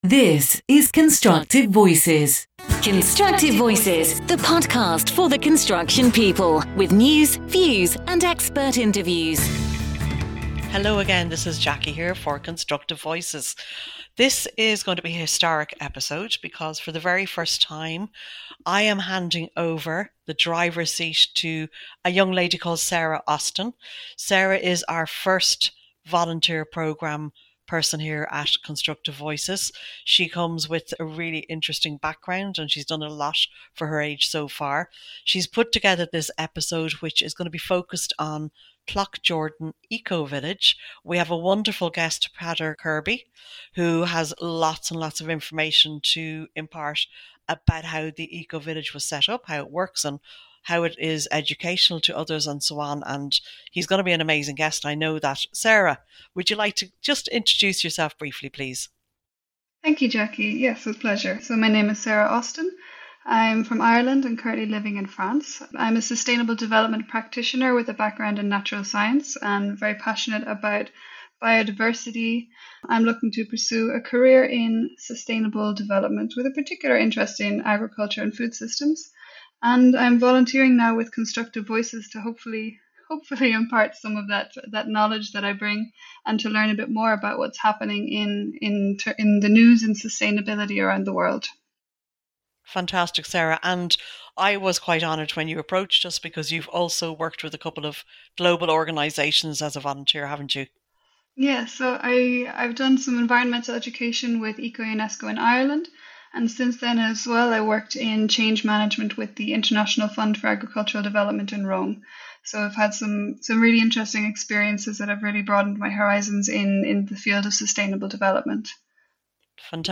This bonus episode features a 12-minute excerpt from a 2021 interview